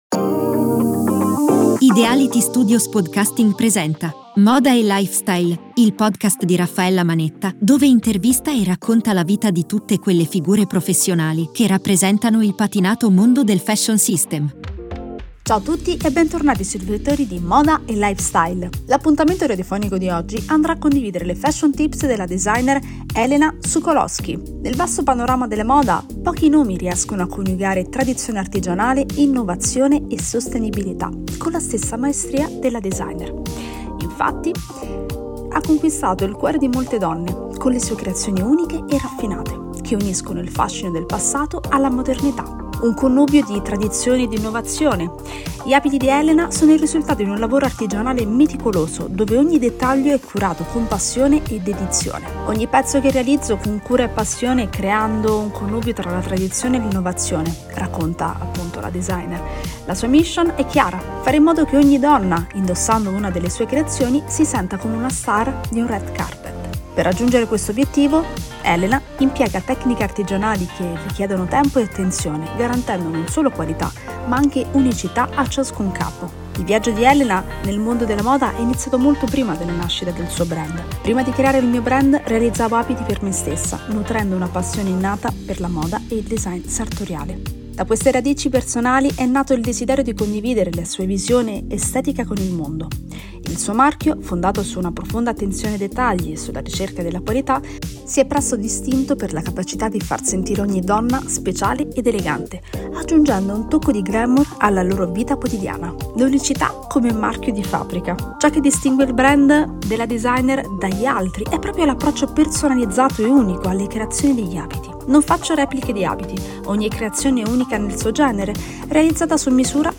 Interviste Radiofoniche